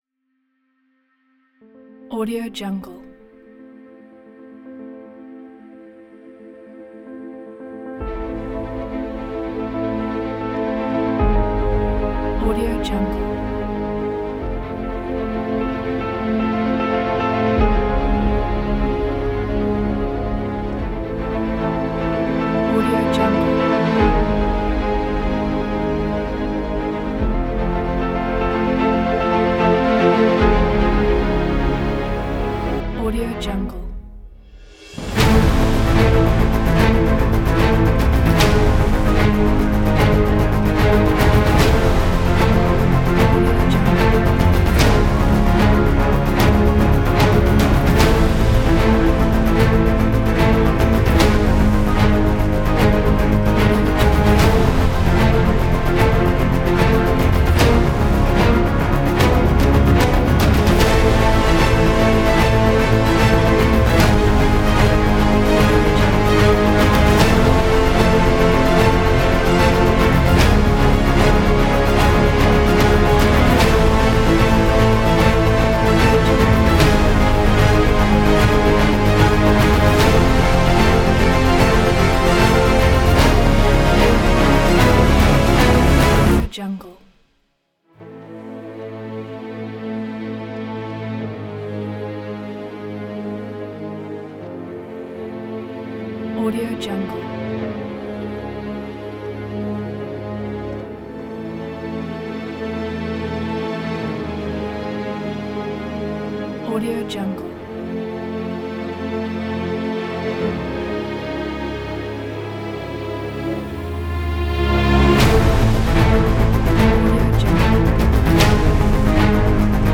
آمبیانس و آرام